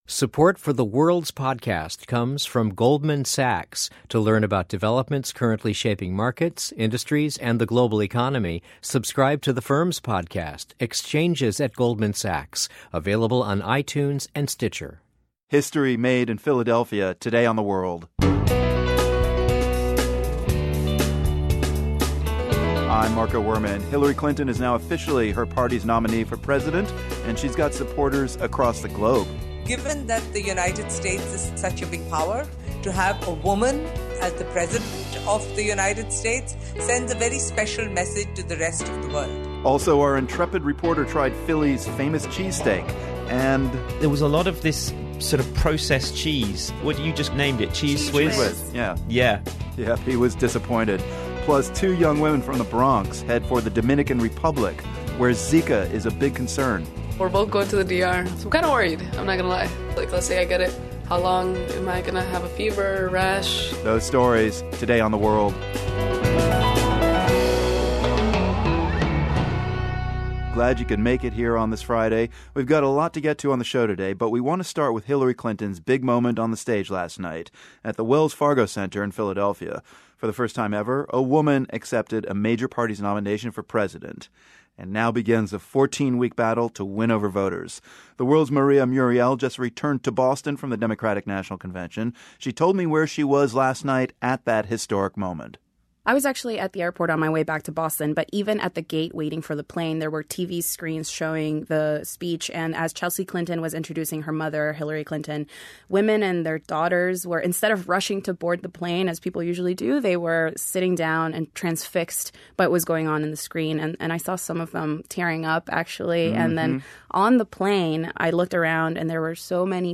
Hillary Clinton makes history as the first woman presidential nominee of a major US party. We get reaction from women in different parts of the globe.
Plus, get a taste of the type of Brazilian music we're likely to hear from the Olympic games.